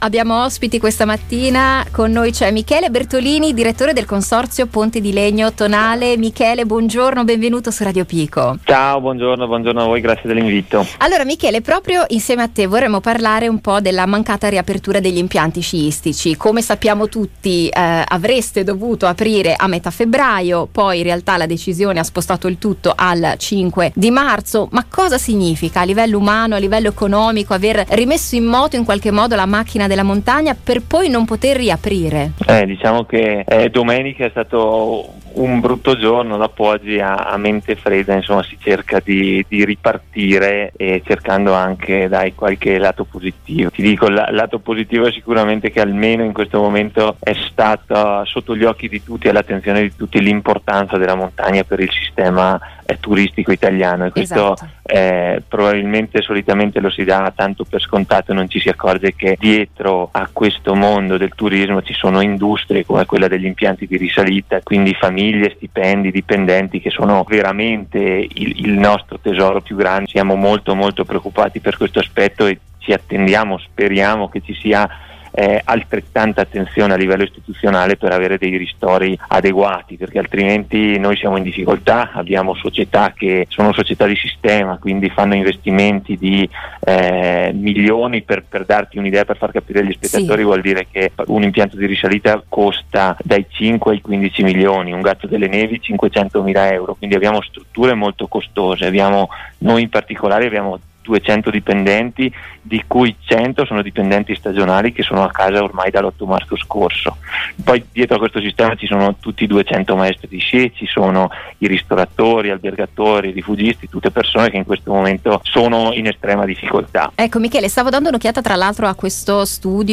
ha raccontato al microfono di Radio Pico qual è la situazione del “sistema montagna” dopo lo stop del Governo alla ripresa dell’attività sciistica. Uno sguardo dall’interno sul lavoro fatto per prepararsi alla riapertura e sulle perdite del settore, ormai fermo da un anno: